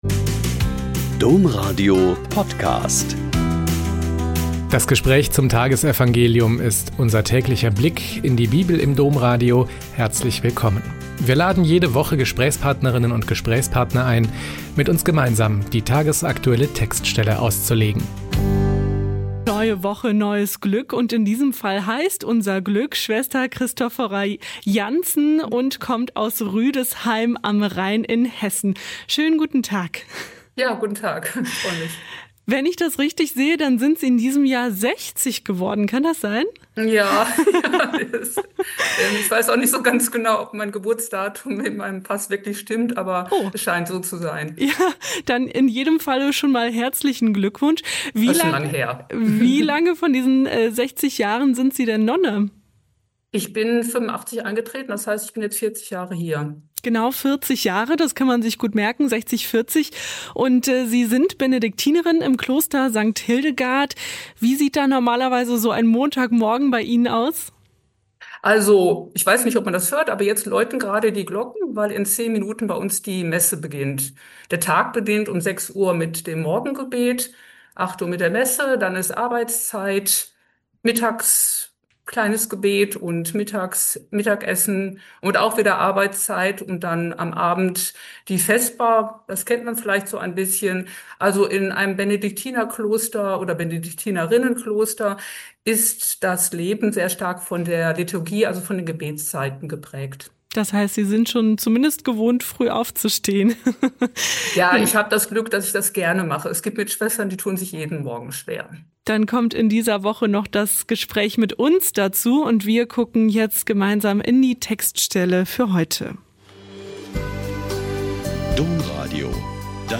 Mt 21,23-27 - Gespräch